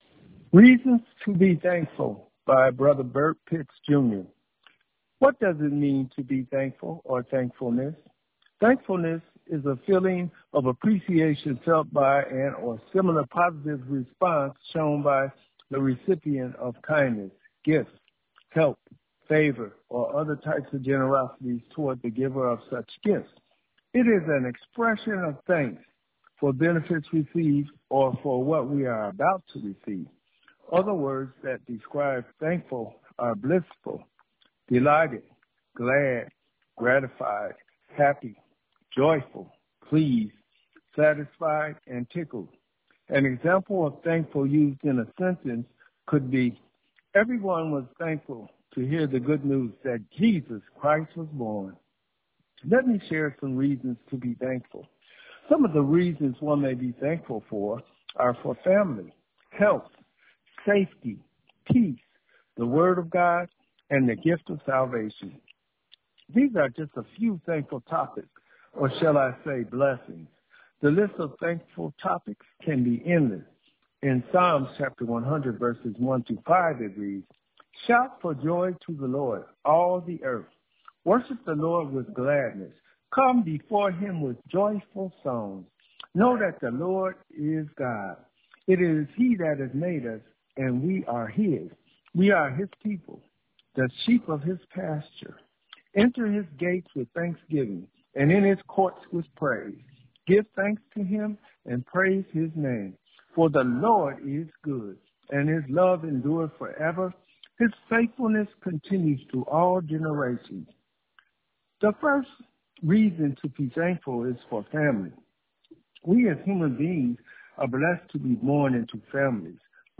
Welcome to our Weekly Worship Service Online
Prayer Meditation: